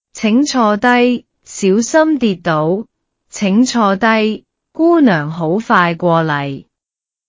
預設語音